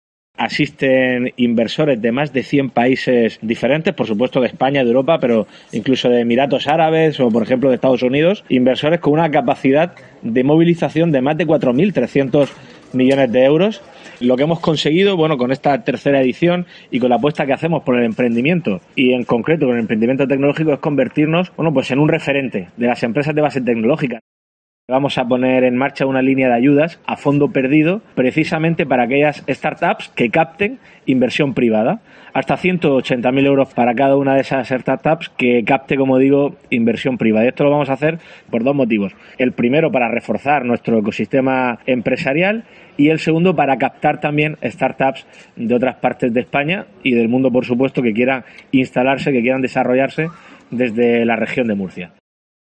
Fernando López Miras, presidente de la Región de Murcia
El presidente del Gobierno autonómico, Fernando López Miras, anunció durante la inauguración del foro de inversores ‘Waykup Murcia Startup Region’ que su Ejecutivo lanzará en 2024 una línea pionera de ayudas para atraer ‘startups’ a la Región de Murcia, es decir, empresas emergentes e innovadoras que operan en el sector de las nuevas tecnologías.